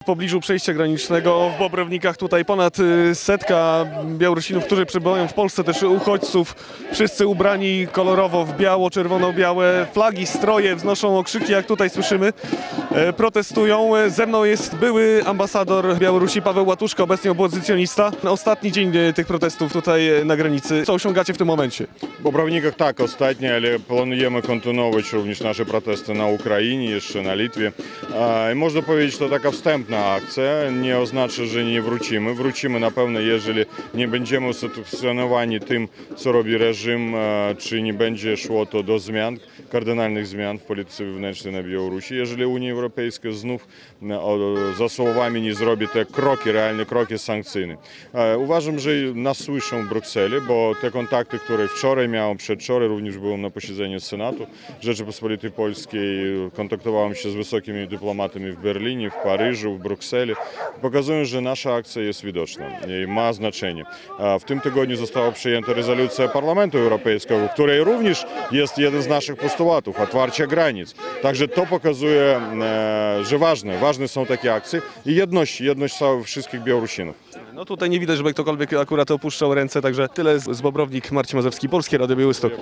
To ostatni dzień akcji na przejściu granicznym w Bobrownikach - protestuje ponad stu Białorusinów - relacja